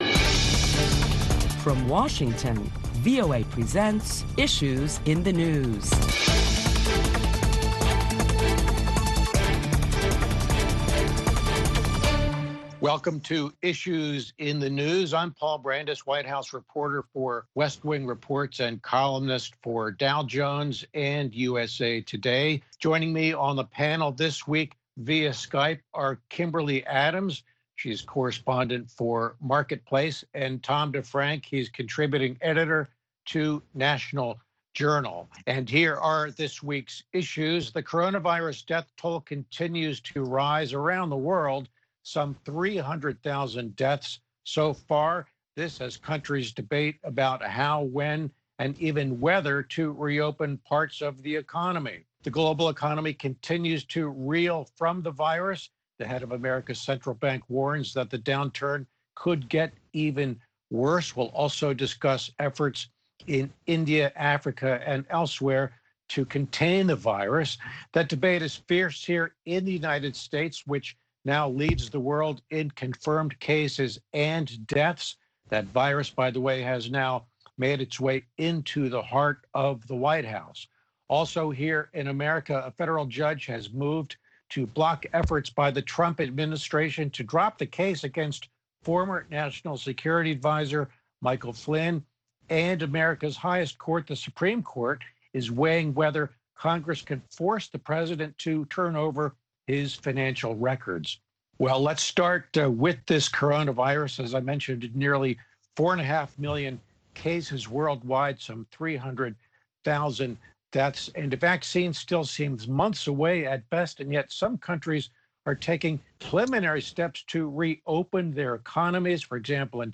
Join a panel of prominent Washington journalists as they deliberate the latest developments on how the world is coping with the coronavirus pandemic.